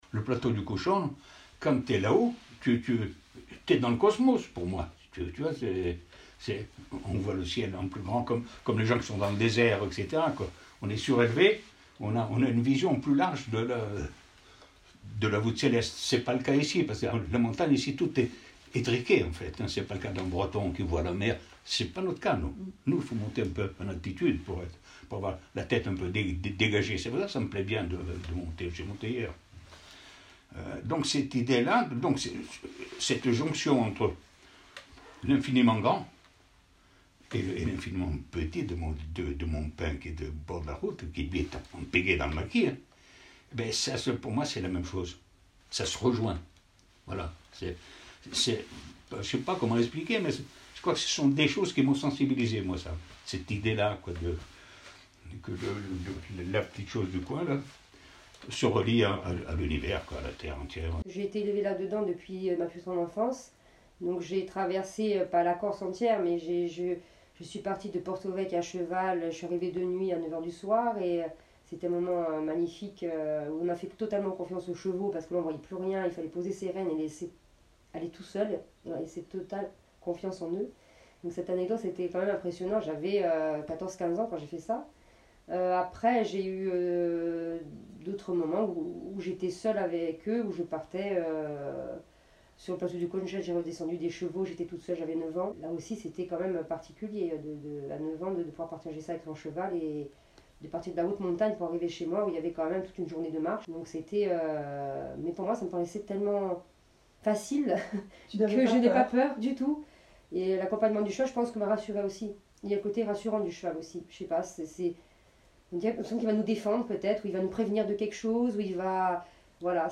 Ecoutez un florilège des extraits d'entretiens